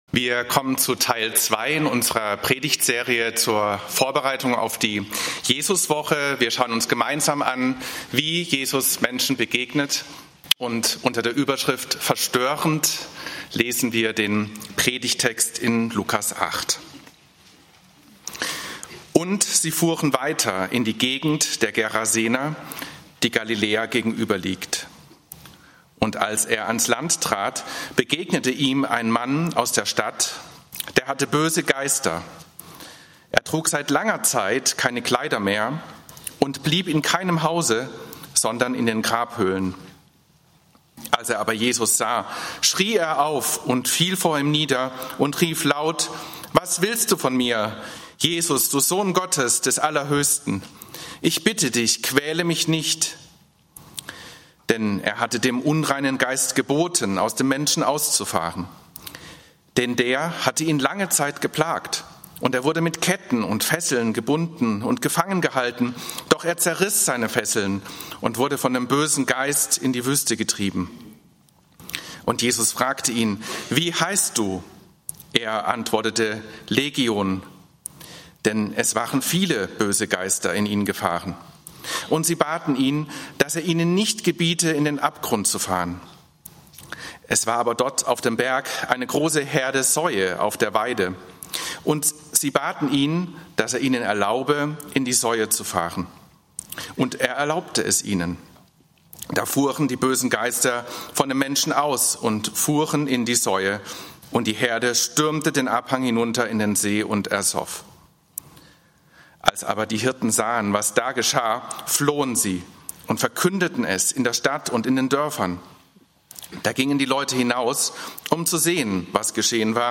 Jesuswoche 2026 - "Verstörend" - Jesusbegegnung bei der Schweineherde (Lk. 8, 26-39) - Gottesdienst